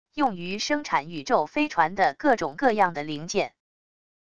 用于生产宇宙飞船的各种各样的零件wav音频